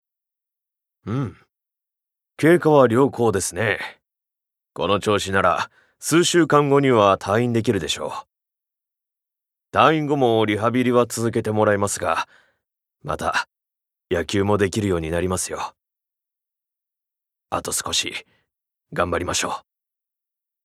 Voice Sample
ボイスサンプル
セリフ５